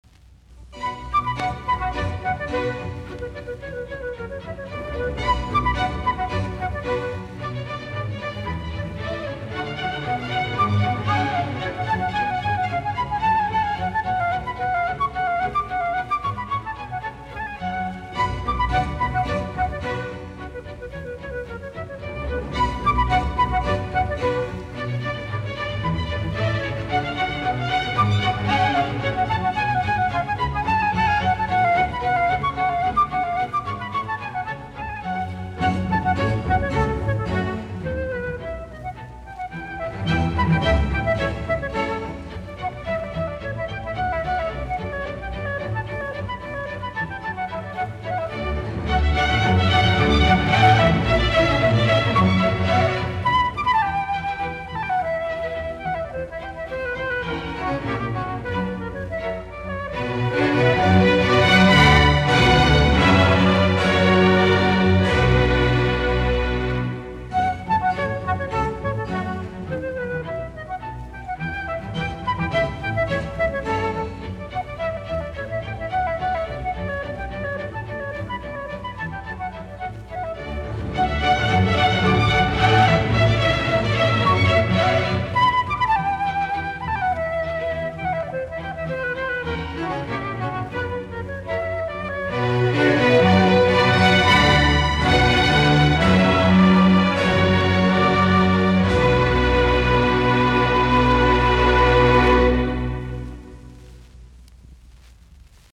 Alkusoitot, kamariork., BWV1067, h-molli
Leopold Stokowski and his symphony orchestra.